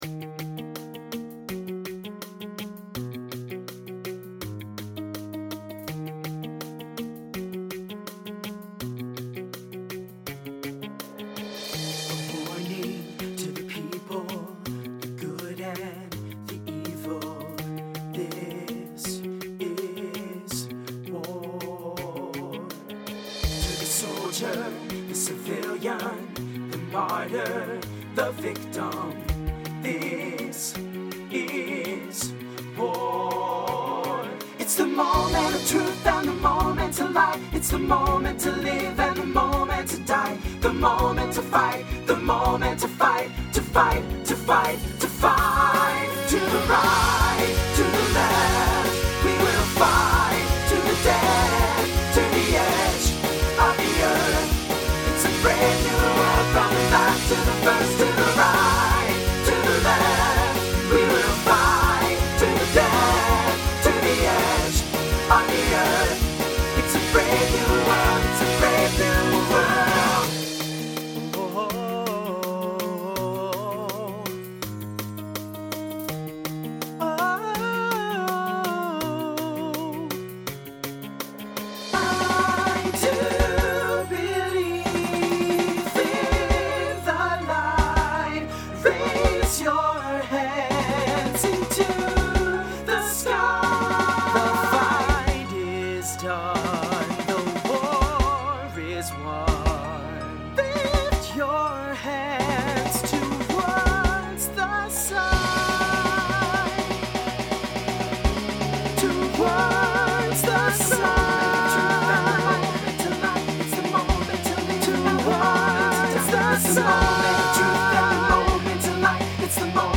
New SAB voicing for 2025.